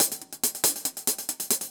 Index of /musicradar/ultimate-hihat-samples/140bpm
UHH_AcoustiHatC_140-02.wav